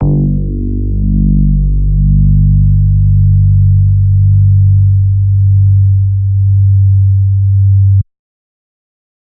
Bass_G_04.wav